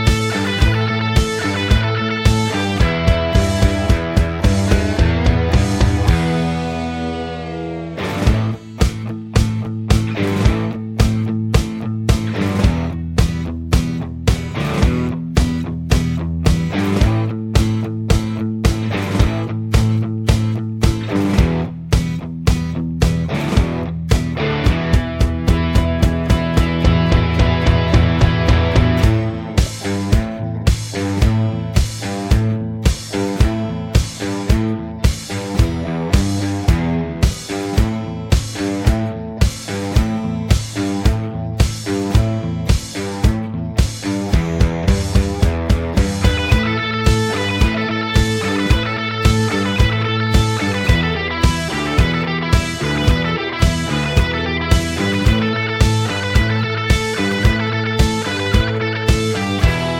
no Backing Vocals Glam Rock 2:58 Buy £1.50